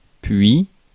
Ääntäminen
IPA : [ˈænd] US